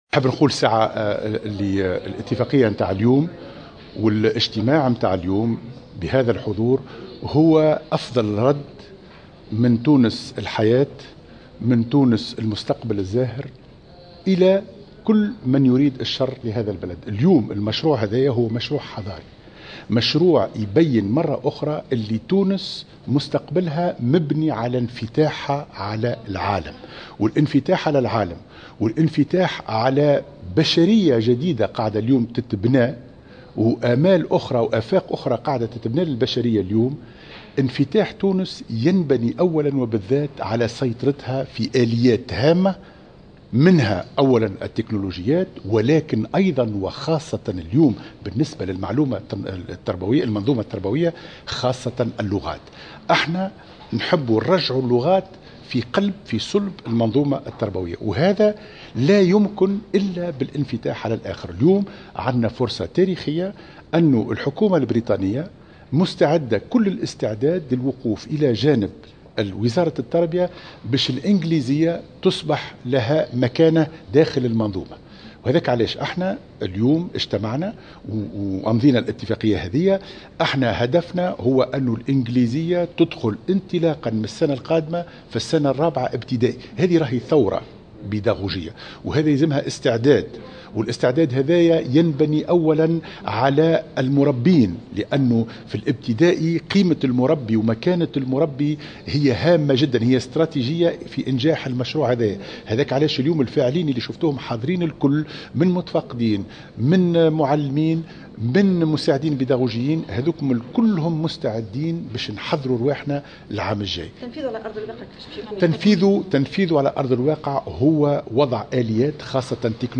وقال في تصريح لمراسلة "الجوهرة اف أم" إن هناك استعدادات حثيثة لهذا الموعد مشيرا إلى مبادرة بريطانية في هذا الصدد من خلال اتفاقية تم توقيعها بين الحكومة التونسية وحكومة المملكة المتحدة البريطانية عن طريق سفارة بريطانيا في تونس، يرمي إلى تكوين مدرسي اللغة الانجليزية ودعمه.
وجاءت هذه التصريحات على هامش انعقاد الندوة الوطنية لتدريس اللغة الانجليزية، حيث أكد أنّه سيتم في اطار هذا المشروع الذي يمتد تنفيذه على مدى 3 سنوات، وضع آليات تكنولوجية خاصة تساعد المتكونين الجدد على تعليم اللغة للتلاميذ على أن ينطلق المشروع من خلال تكوين مجموعة محددة في الجهات وسيتم تسخير كل الامكانيات للبدء في تدريس اللغة الانقليزية في السنة الرابعة ابتدائي مع انطلاق السنة الدراسية المقبلة.